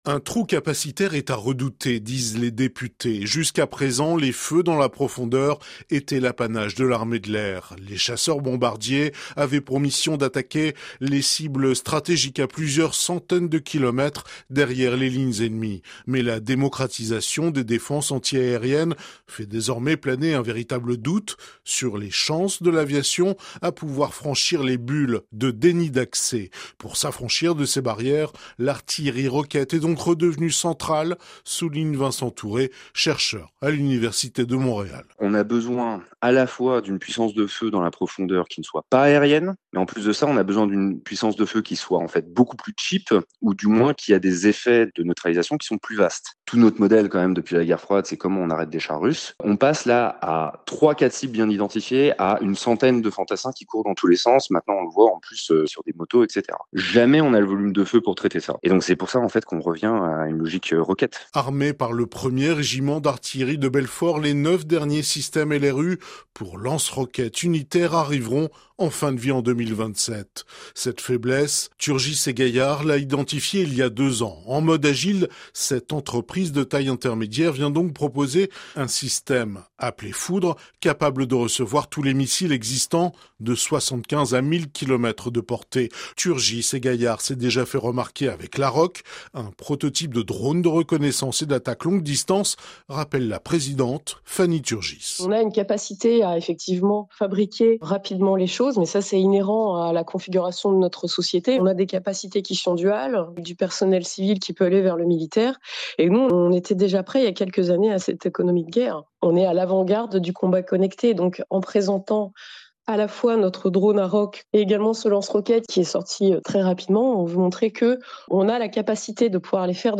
La section technique de l’armée de Terre a présenté, lors d’un exercice d’évaluation, ses capacités nouvelles du combat aéroterrestre le mercredi 4 décembre sur le plateau du Larzac dans le sud de la France. Essaims de drones, drones suicides, capacité de cartographie, l’exercice avait pour objectif de démontrer la montée en puissance des drones dans les forces. Reportage